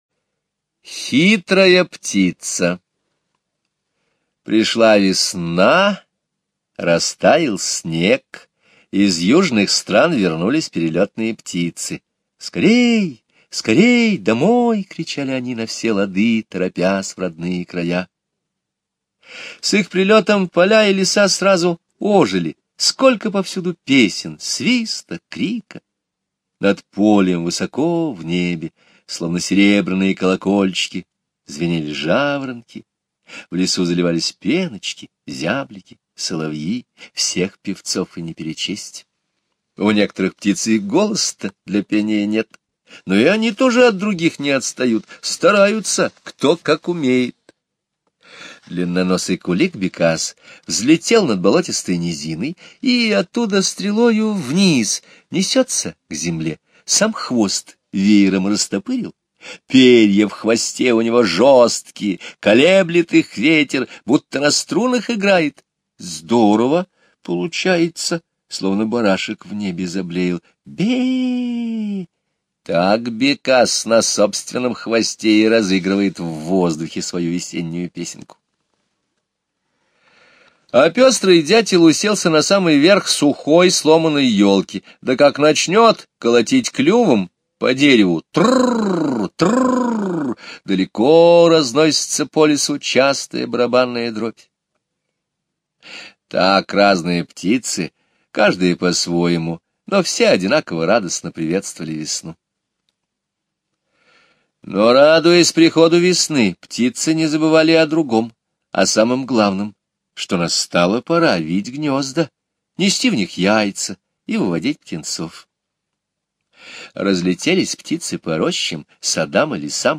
Хитрая птица - Скребицкий - слушать рассказ онлайн